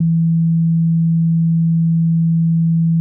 CX_TONE.WAV